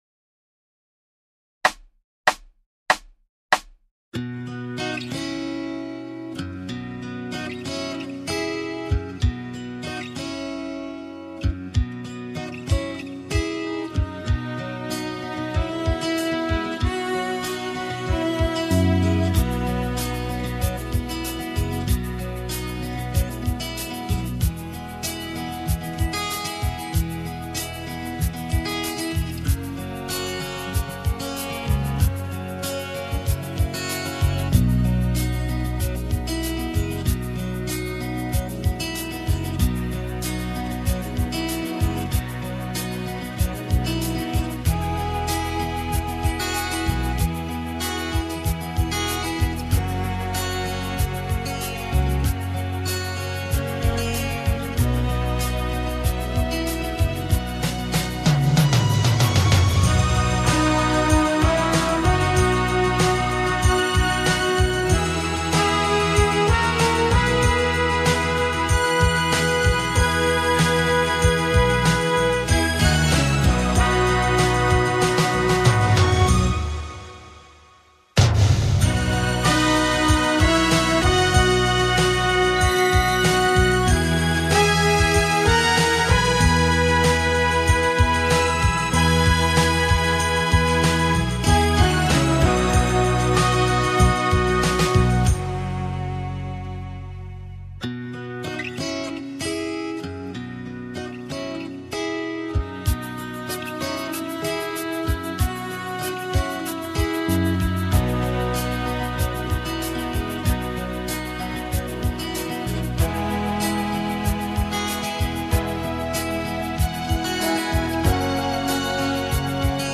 Genere: Moderato